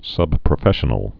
(sŭbprə-fĕshə-nəl)